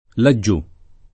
vai all'elenco alfabetico delle voci ingrandisci il carattere 100% rimpicciolisci il carattere stampa invia tramite posta elettronica codividi su Facebook là giù [ la JJ2+ ] (ant. là giuso [ la JJ2@ o o la JJ2S o ]) → laggiù